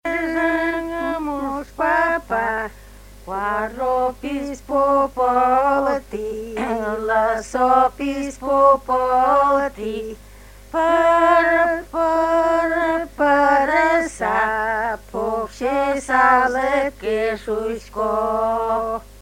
«Муш утён гур» (бортничья песня
Место фиксации: Удмуртская республика, Киясовский район, деревня Карамас-Пельга